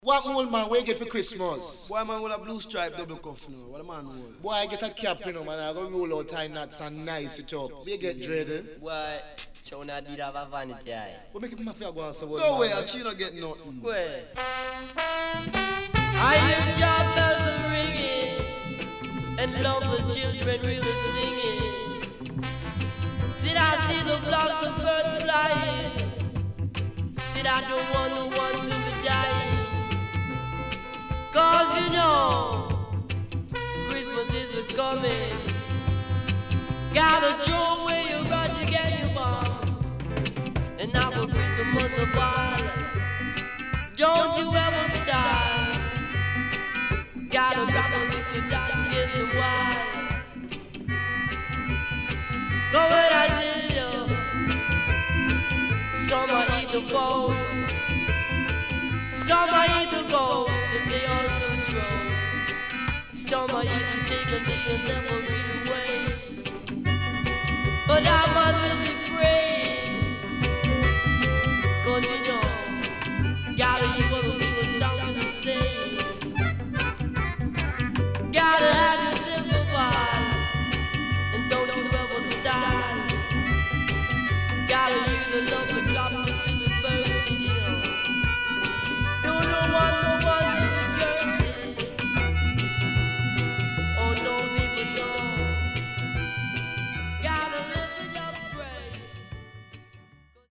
HOME > REGGAE / ROOTS  >  INST 70's
CONDITION SIDE A:VG(OK)〜VG+
SIDE A:所々チリノイズがあり、少しプチノイズ入ります。